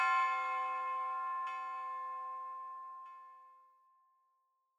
MB Church Bell.wav